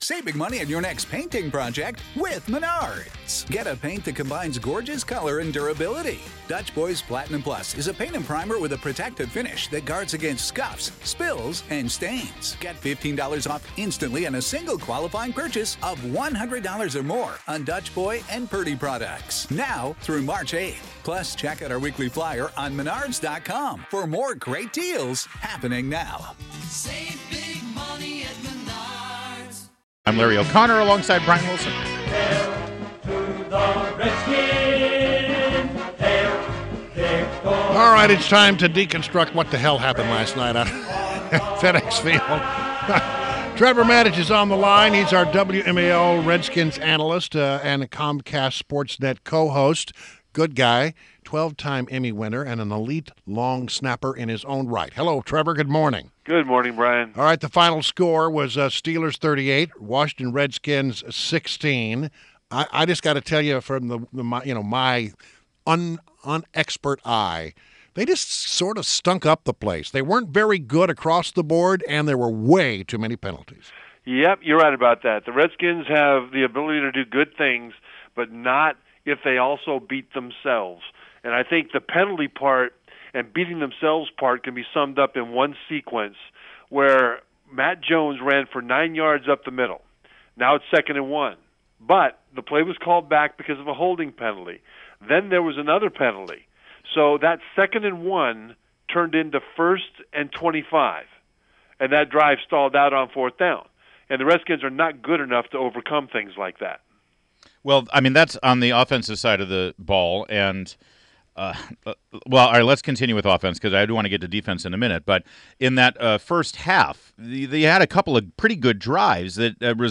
WMAL Interview - TREVOR MATICH - 09.13.16